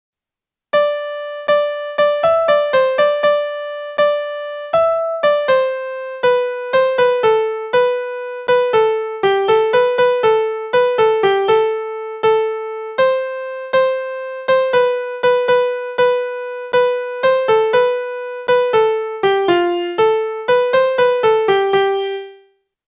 The first verse and melody of the first song are incomplete.
There are no editorial changes to note values.